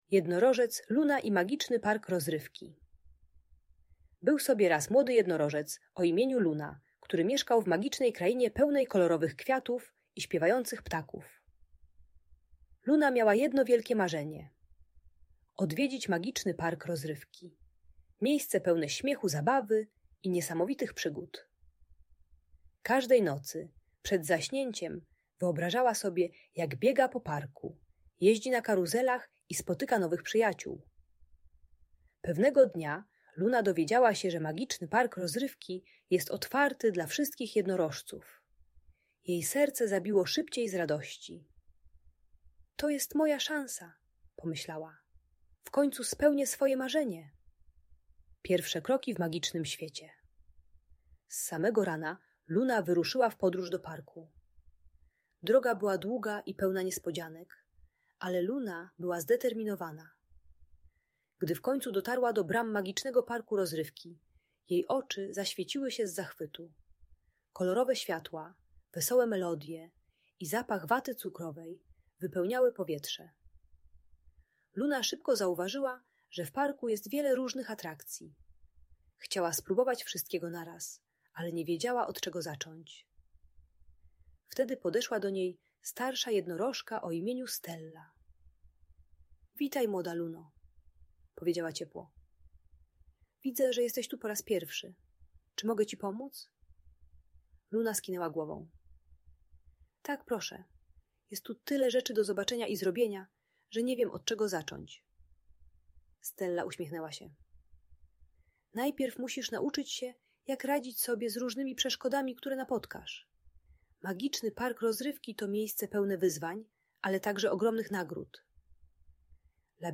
Historia Jednorożca Luny i Magicznego Parku Rozrywki - Audiobajka